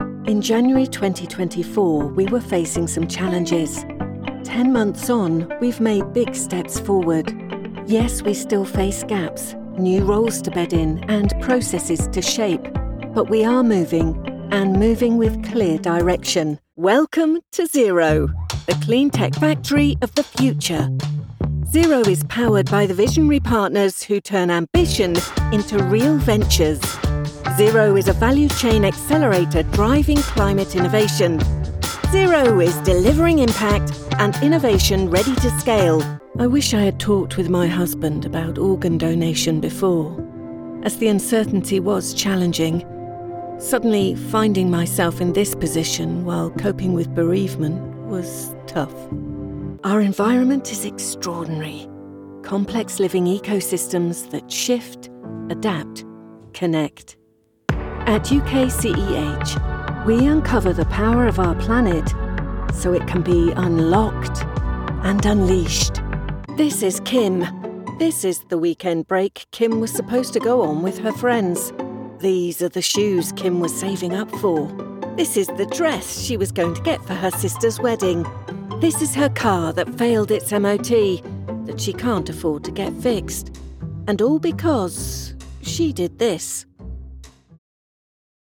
warm, husky contemporary tones ideal for a funky ad.. but then she spins on a dime and can morph into a classically trained actor with beautiful Standard English Pronunciation....
English (British) Adult (30-50) | Older Sound (50+)